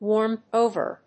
アクセントwármed‐óver
音節warmed-o･ver発音記号・読み方wɔ́ːrmdòʊvər